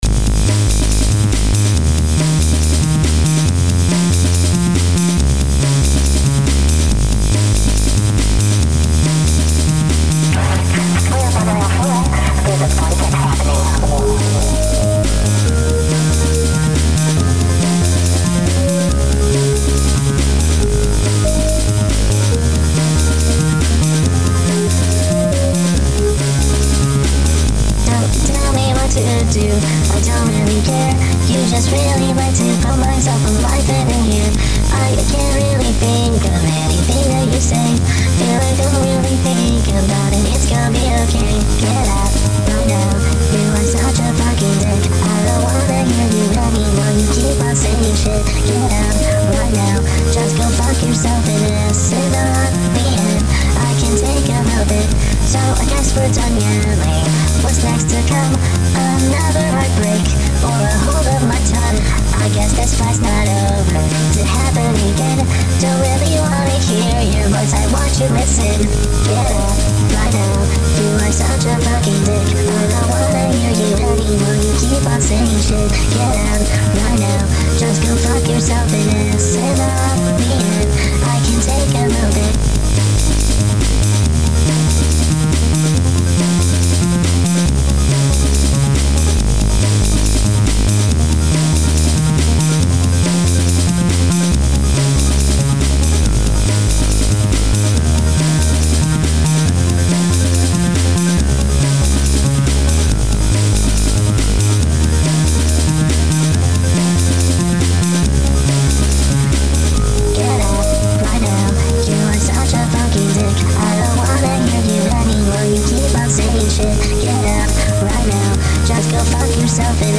[ "hyperpop", "edm", "pop" ]
[ "TECHNO", "POP" ]